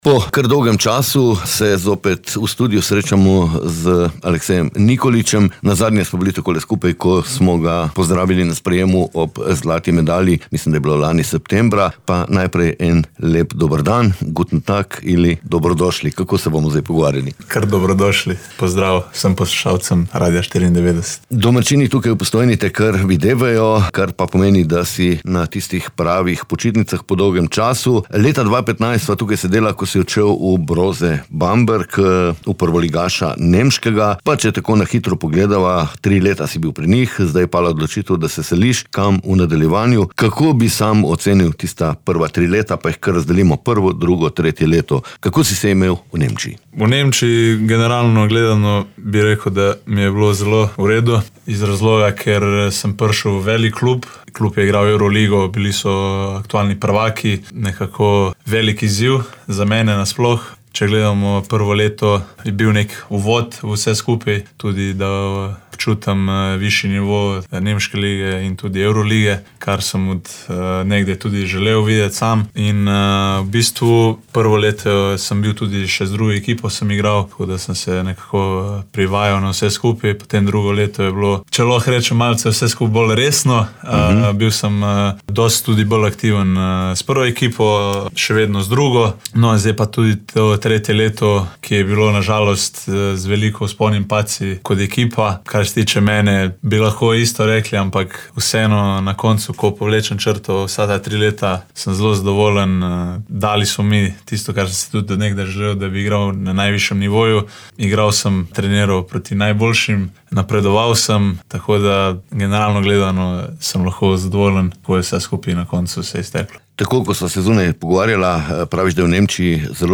Kako gleda na 3 leta preživeta v Bambergu in kako na prihodnost v Beogradu, nam je zaupal v sproščenem pogovoru.